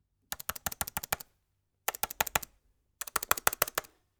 Computer keyboard - Pressing fast and hard
angry annoyed button click clicks computer effect fast sound effect free sound royalty free Sound Effects